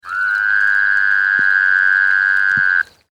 The advertisement call of the Rocky Mountain Toad is a loud bleating or snoring sound that has been compared to a muted sheep or calf bleating, or a snore, lasting 1 - 4 seconds.
Sound This is a very short recording of one solo call from a male toad in Franklin County, Washington.